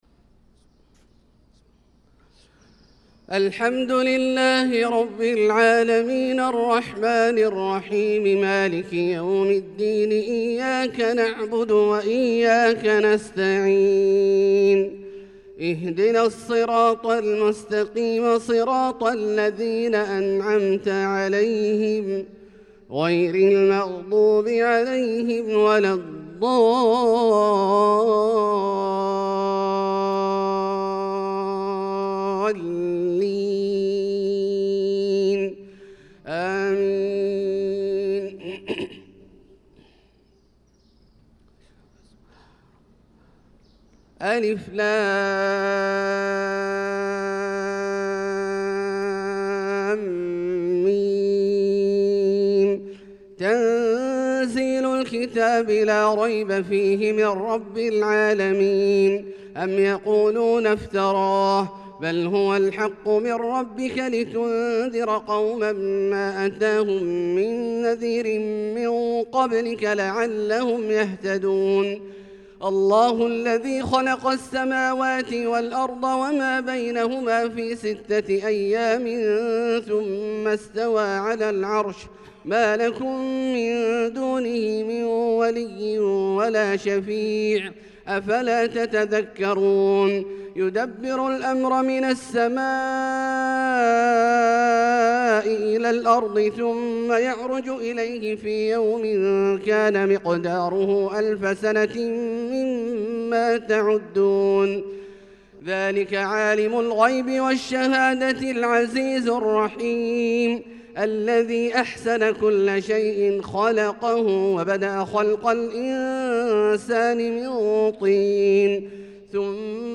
صلاة الفجر للقارئ عبدالله الجهني 17 شوال 1445 هـ
تِلَاوَات الْحَرَمَيْن .